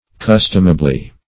customably - definition of customably - synonyms, pronunciation, spelling from Free Dictionary Search Result for " customably" : The Collaborative International Dictionary of English v.0.48: Customably \Cus"tom*a*bly\, adv.